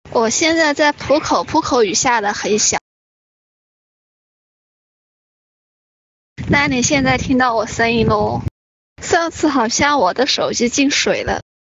Kojący Głos Lektora do Bajek na Dobranoc
Przekształć opowieści dla dzieci w immersyjne doświadczenia ułatwiające zasypianie dzięki delikatnemu, ciepłemu głosowi AI stworzonemu z myślą o relaksie i zachwycie.
Tekst na Mowę
Delikatna Narracja
Uspokajający Wokal
Bajki na Dobranoc
Naturalna kadencja i ekspresyjna intonacja dostosowane do długich opowieści.